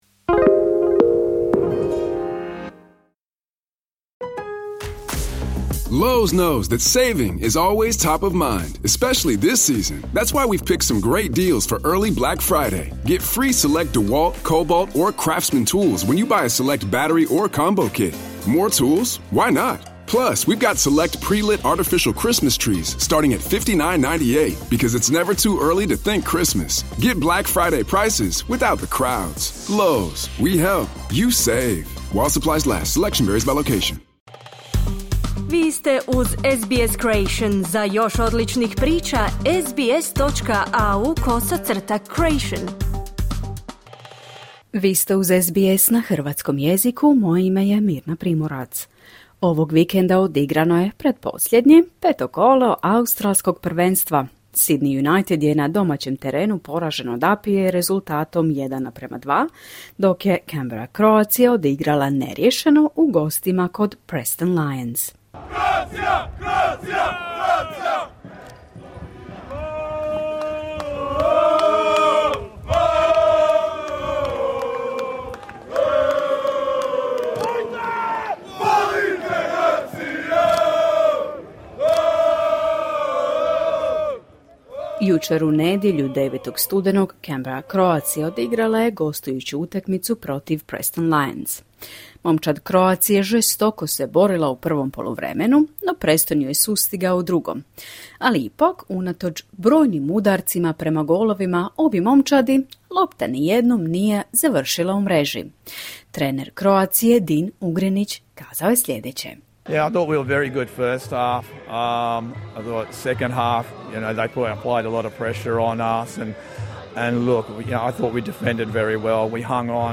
Canberra je u gostima kod Preston Lions osvojila bod, dok je Sydney United poražena od APIE rezultatom 1:2. Treneri, igrači i predsjednici klubova komentirali su igru, atmosferu i planove za završnicu prvenstva. Poslušajte izvješće s utakmice Canberra Croatia i Preston Lions.